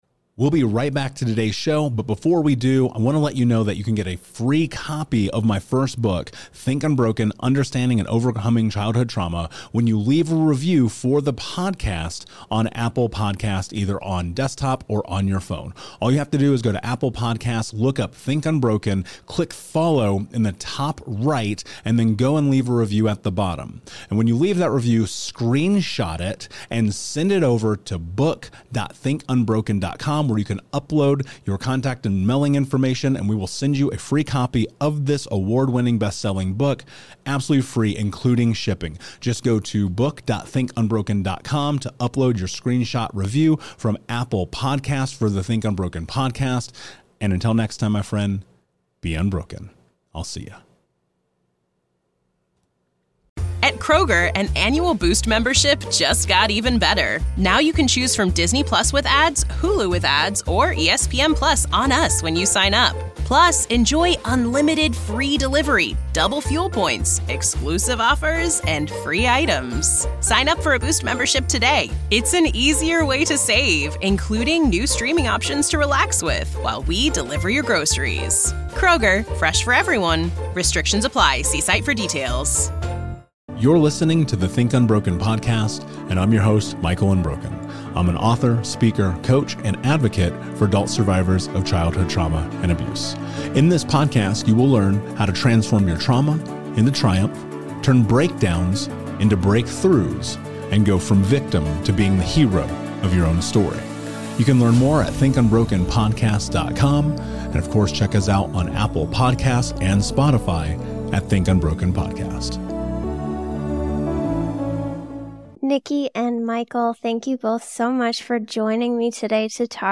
insightful conversation on cultivating resilience. They touch on overcoming trauma, breaking dysfunctional patterns, the journey of sobriety, and what resilience truly means.